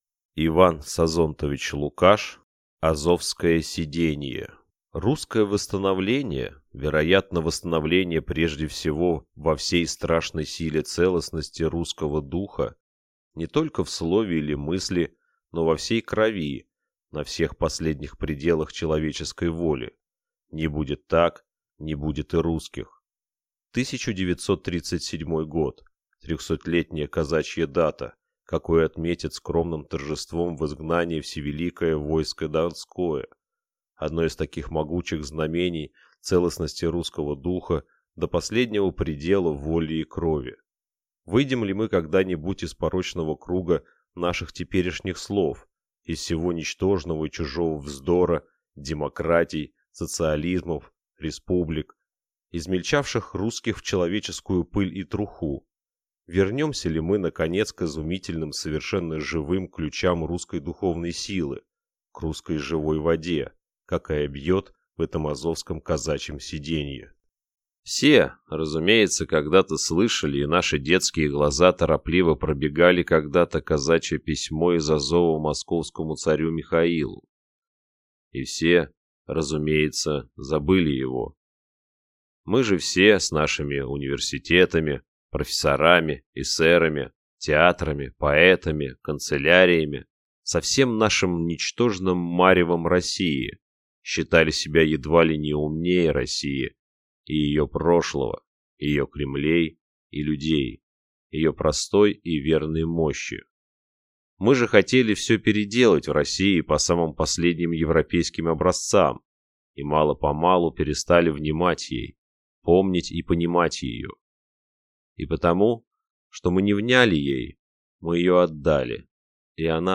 Аудиокнига Азовское сидение | Библиотека аудиокниг